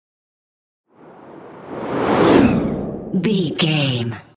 Whoosh airy
Sound Effects
futuristic
whoosh